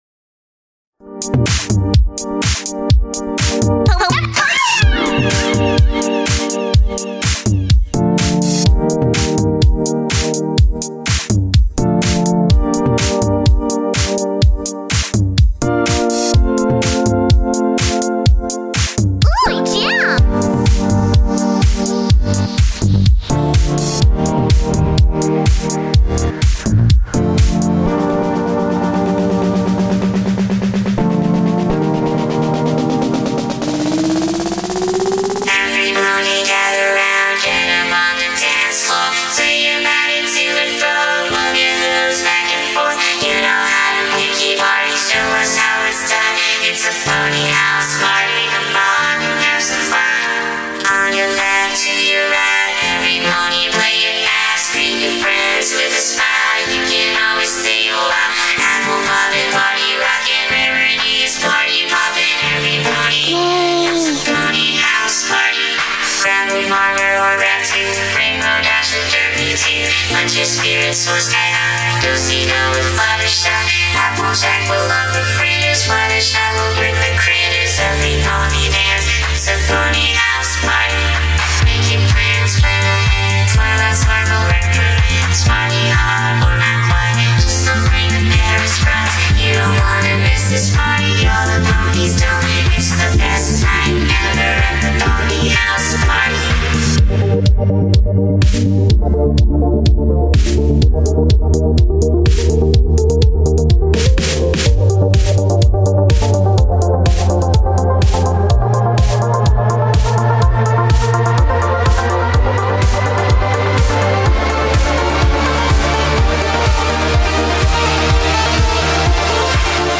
Pony Voice Samples